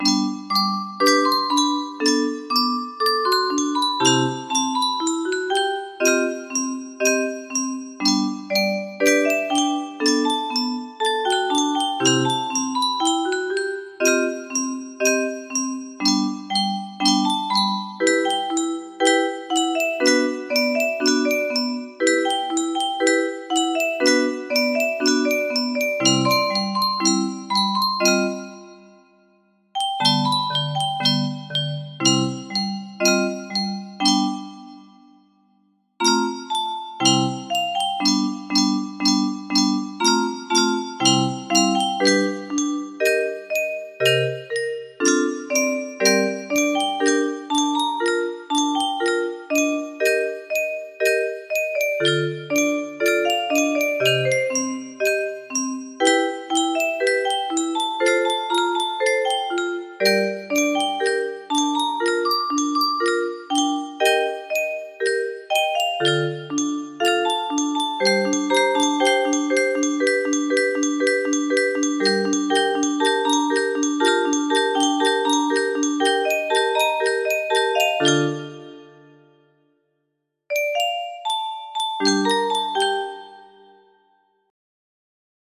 HUG2 music box melody